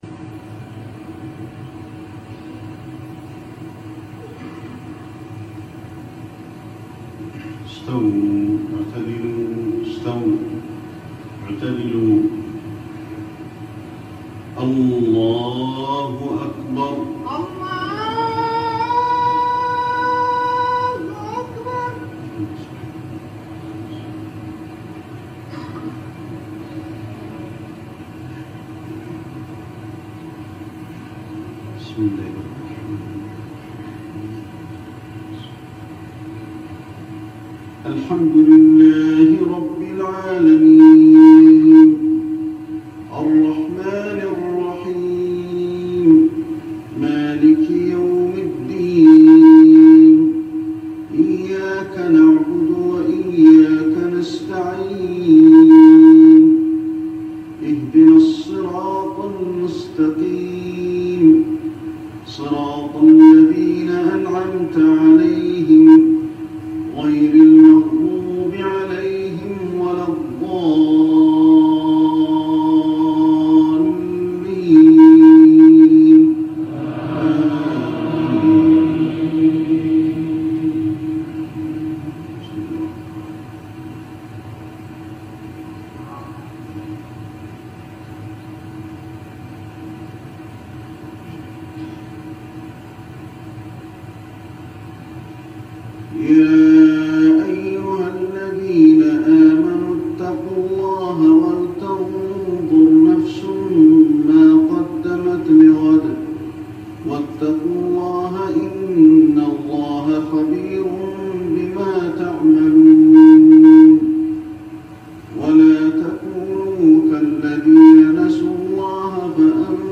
صلاة المغرب الركعة الأولى من سورة الحشر ١٨-٢١ | فترة الثمانينات - المسجد النبوي > 1405 🕌 > الفروض - تلاوات الحرمين